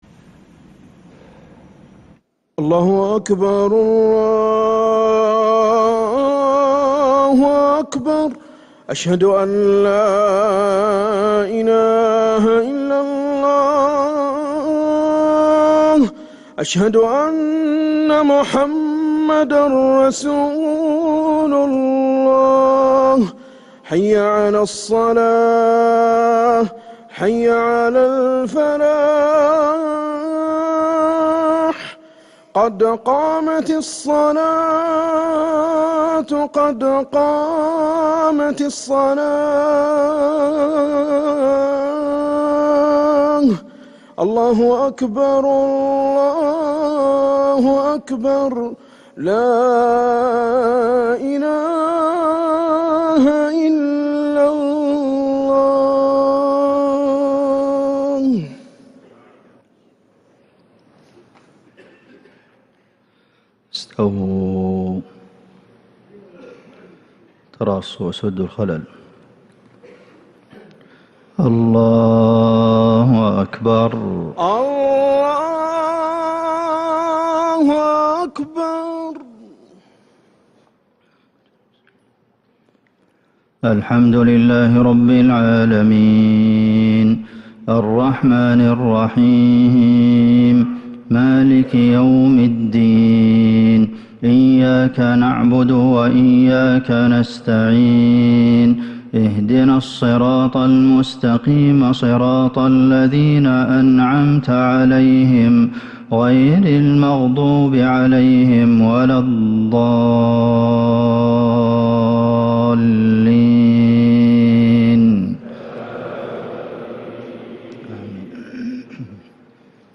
Madeenah Isha - 17th January 2026